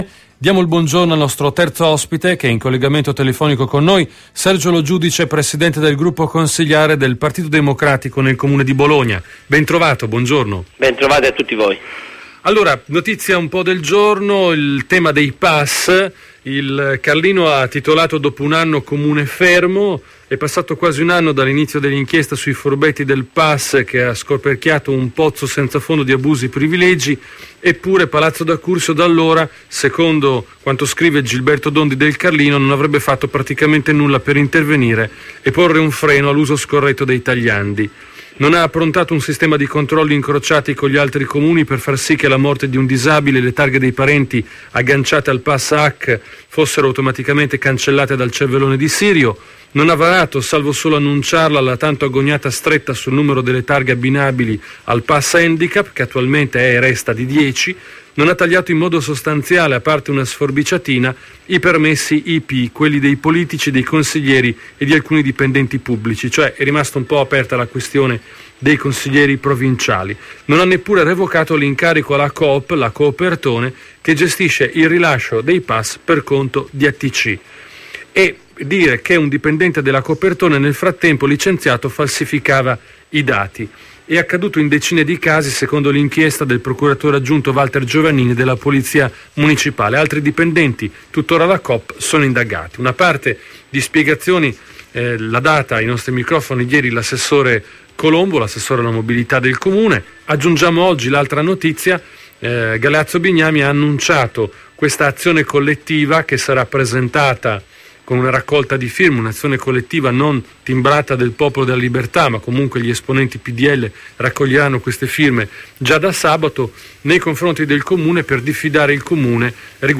Intervista a Radio Tau del capogruppo PD Sergio Lo Giudice il 1 marzo 2012